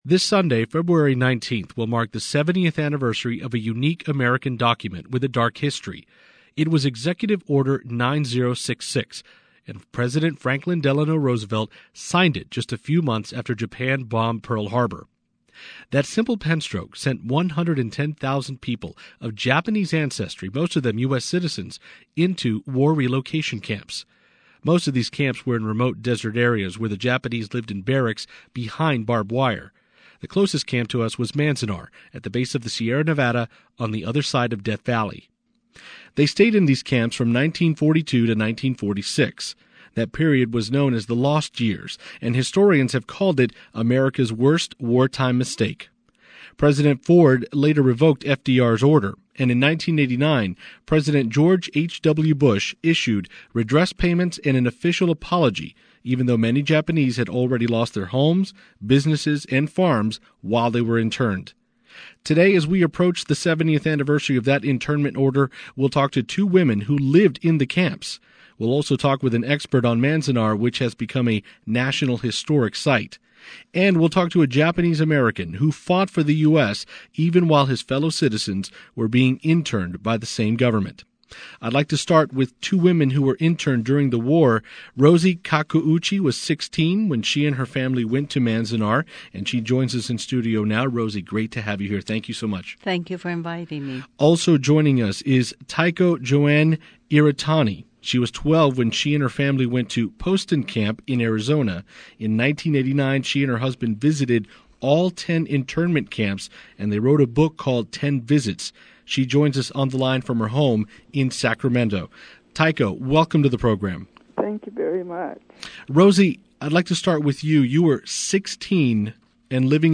70th Anniversary of Executive Order 9066: Japanese American Incarcerees Tell Their Stories To National Public Radio In Nevada
If you want to hear the personal stories of former incarcerees, this radio show is highly recommended.